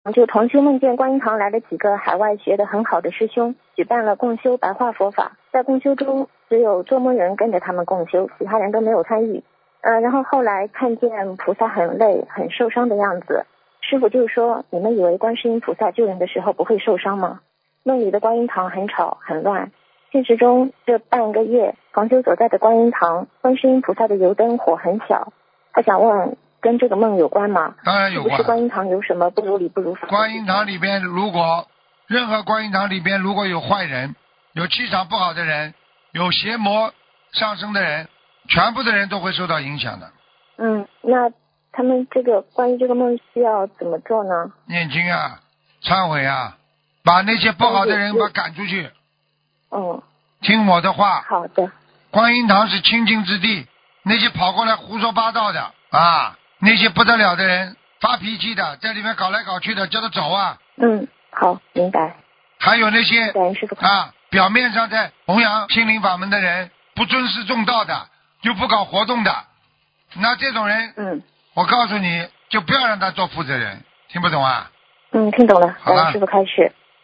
目录：2018年12月_剪辑电台节目录音_集锦